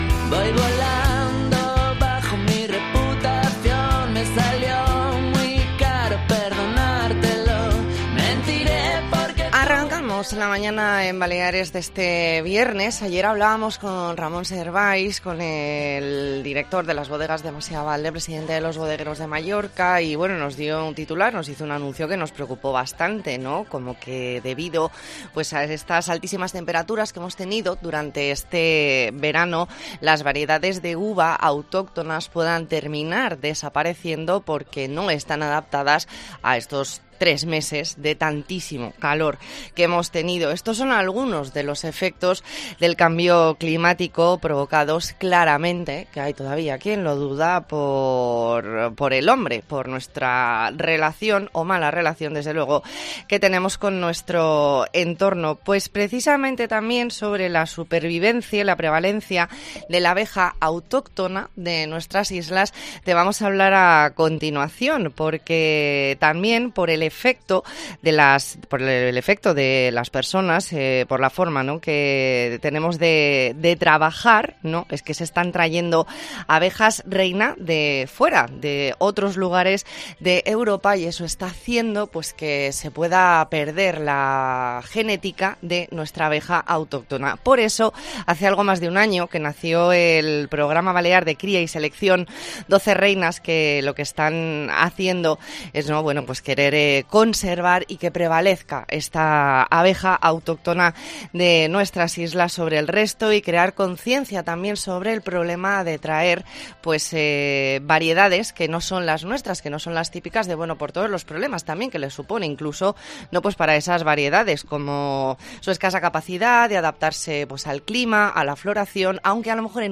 E ntrevista en La Mañana en COPE Más Mallorca, viernes 2 de septiembre de 2022.